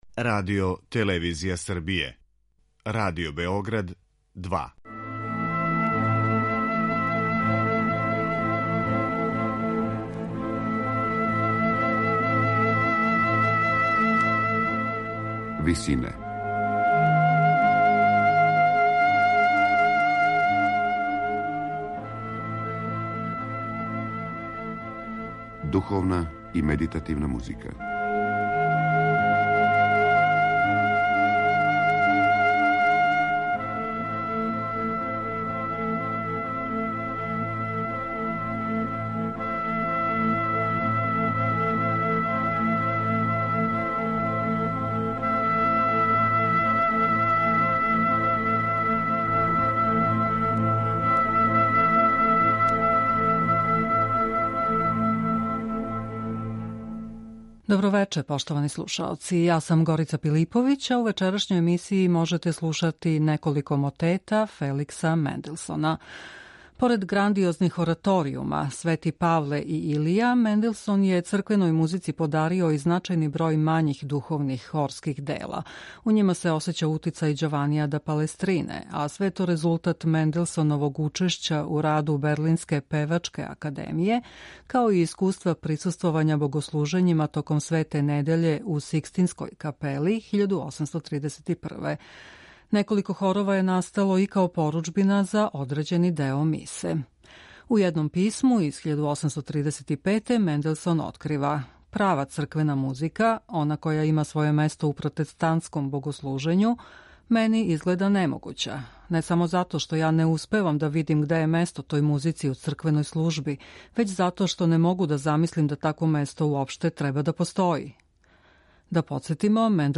На крају програма, у ВИСИНАМА представљамо медитативне и духовне композиције аутора свих конфесија и епоха.
Поред грандиозних ораторијума „Св. Павле" и „Илија", Менделсон је црквеној музици подарио и значајан број мањих духовних хорских дела. У њима се осећа утицај Ђованија да Палестрине, а све је то резултат Менделсоновог учешћа у раду берлинске Певачке академије, као и искуства које је стекао док је присуствовао богослужењима током Свете недеље у Сикстинској капели 1831. године.